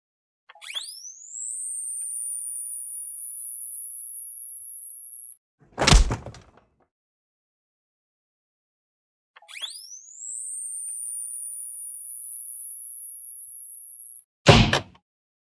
Дефибриллятор спасающий жизни